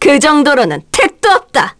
Morrah-Vox_Victory_kr.wav